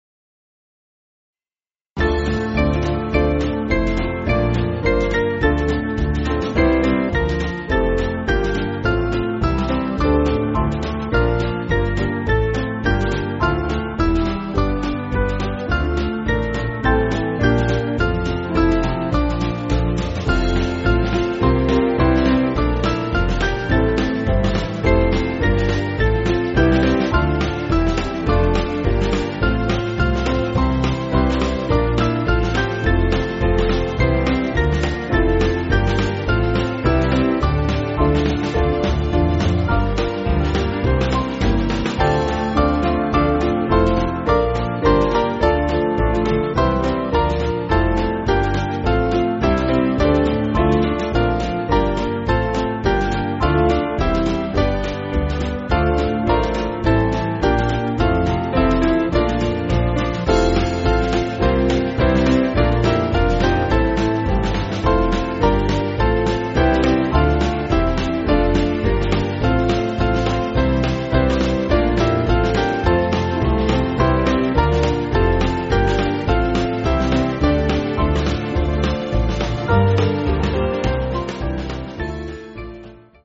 Small Band
(CM)   4/Dm